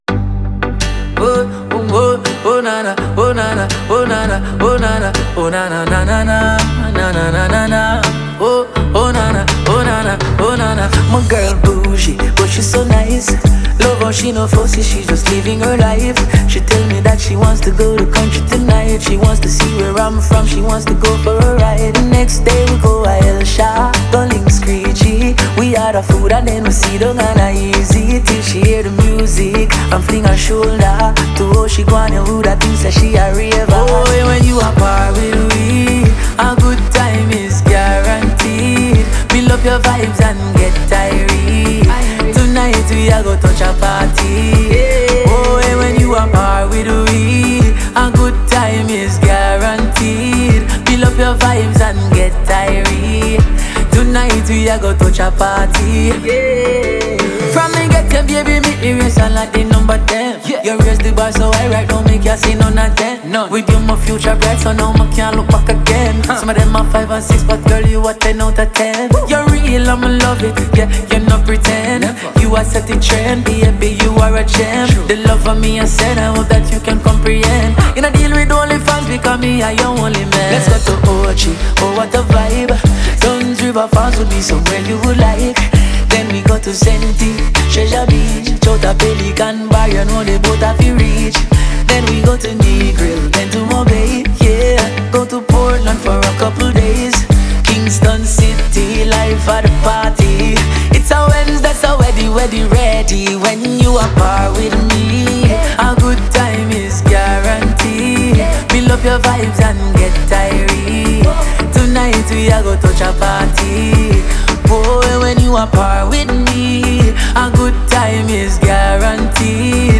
reggae et du dancehall
Cette chanson aux bonnes vibrations